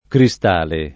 cristale [ kri S t # le ]